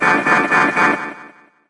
evil_rick_kill_vo_02.ogg